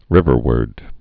(rĭvər-wərd) also riv·er·wards (-wərdz)